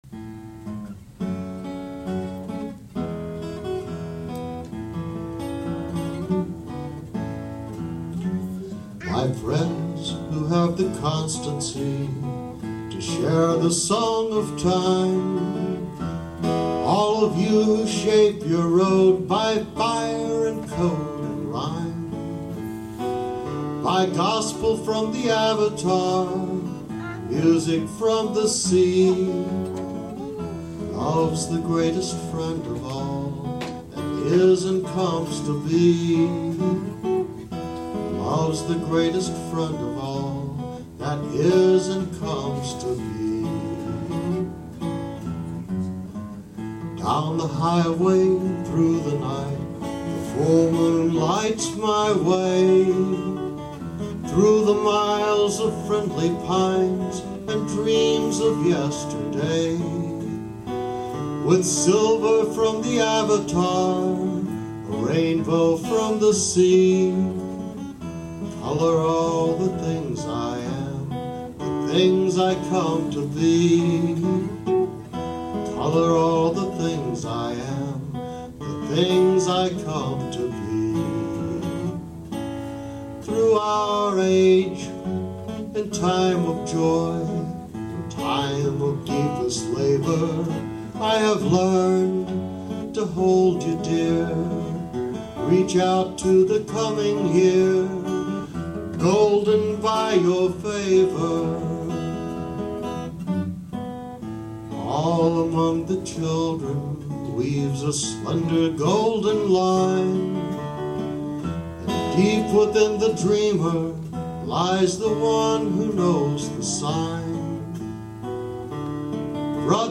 Opening at Live Oak Coffeehouse, November 9, 2001
For those of you interested in the technical details: She made the recording from the audience, using a pair of miniature Core Sound "stealth" microphones clipped to her glasses. I took the audio into my Roland VS-880EX, tweaked the EQ a bit, added track markers, and burned a CD.
"Benediction" (Live version)
BenedictionLive.mp3